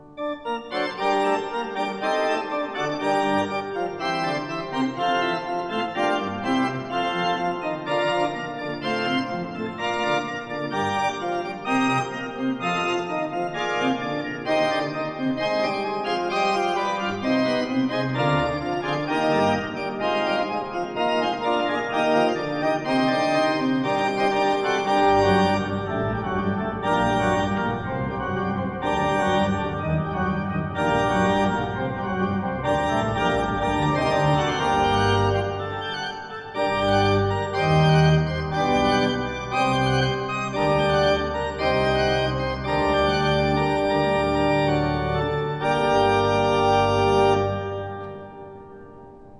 1962 stereo recording